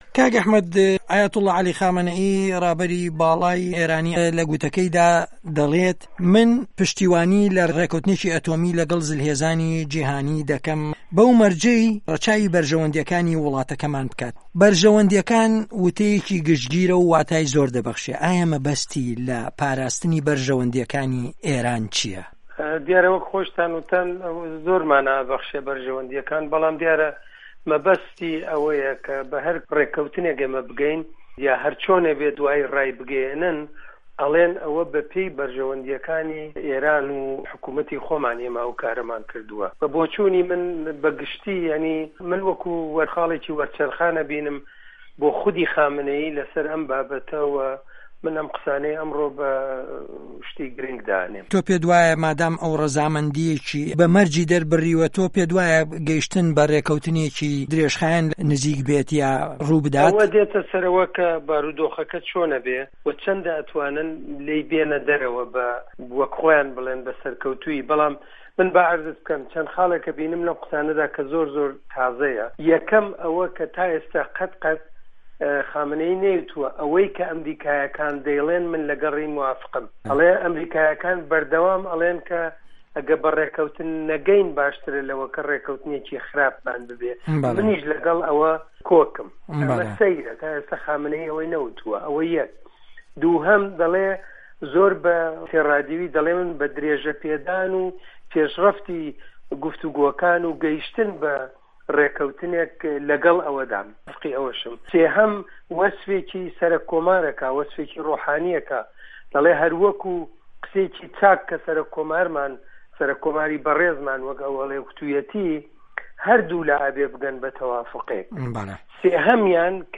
وتوێژی